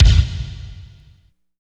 28.10 KICK.wav